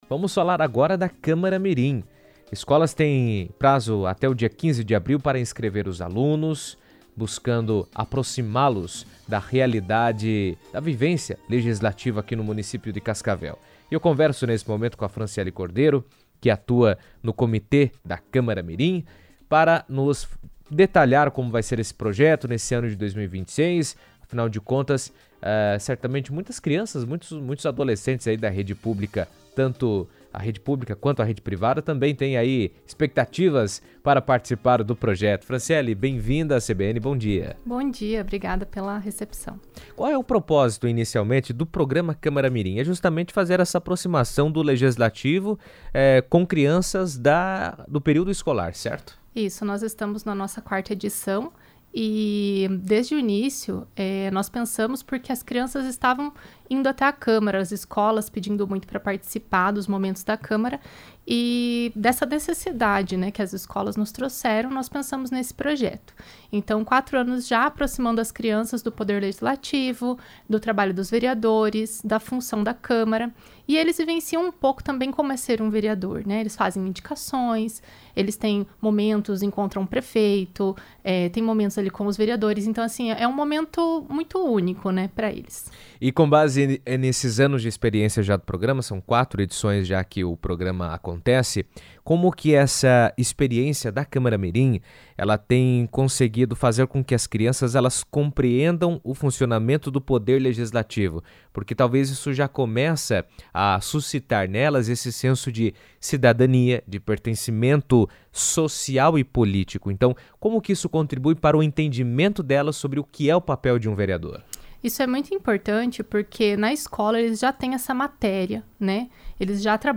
O programa Câmara Mirim tem como objetivo aproximar os estudantes do Poder Legislativo Municipal, permitindo que as crianças conheçam o trabalho dos vereadores, participem de atividades voltadas à educação para a cidadania e aprendam na prática conceitos estudados na escola. Em entrevista à CBN